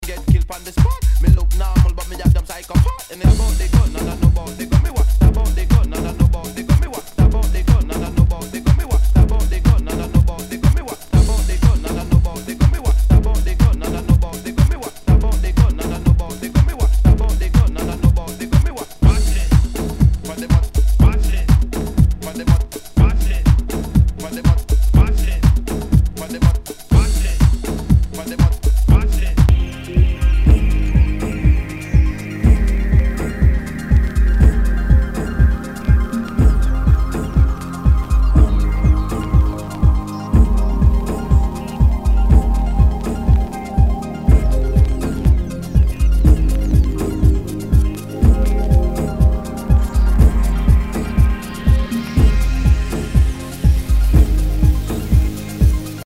HOUSE/TECHNO/ELECTRO
ディープ・ハウス・クラシック！
全体にチリノイズが入ります